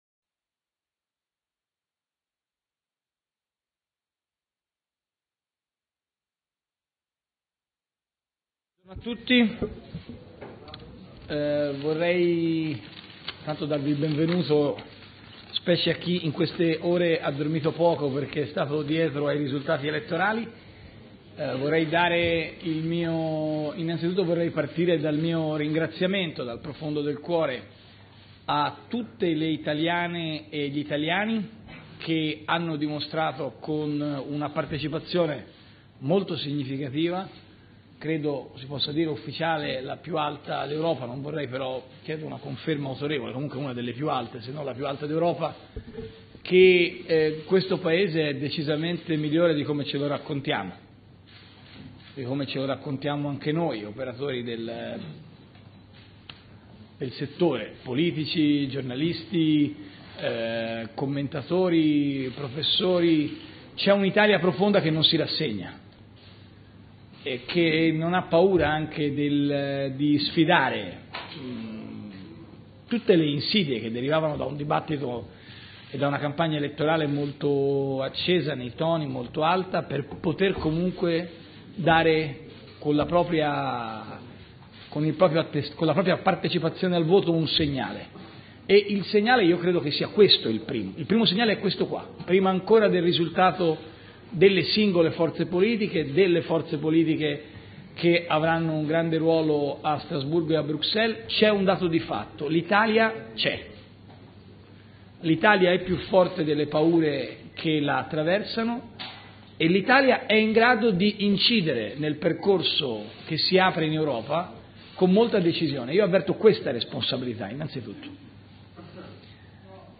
Elezioni europee del 26 maggio 2014: i risultati e la conferenza stampa di Matteo Renzi,